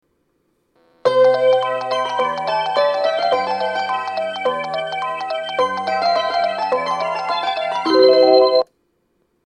１番線発車メロディー 曲は「Bellの響き」です。